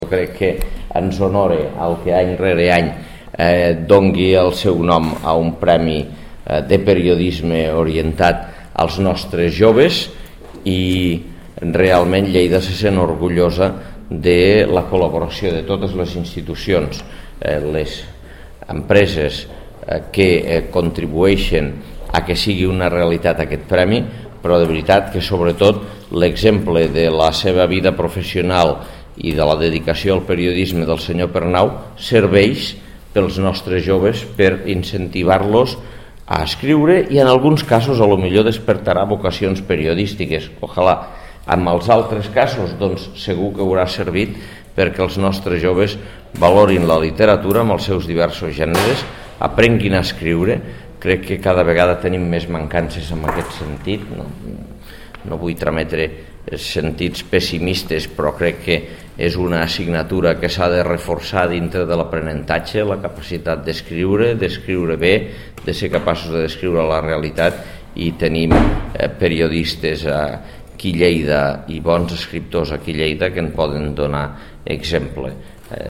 Declaracions Àngel Ros (1.2 MB) Josep Pernau explica el concepte d'oci creatiu amb què denominaven els americans les xerrades a les redaccions entre els periodistes veterans i els joves.